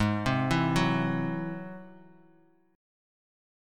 Ab+7 chord